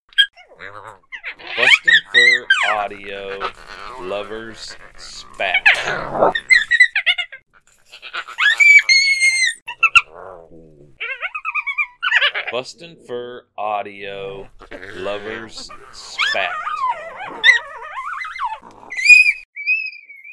Cash and Bobby were recently paired together as a breeding pair and as usual, the fighting and bickering ensued.  Packed with growls, squalls and yips, making it an excellent sound to run during the territorial season.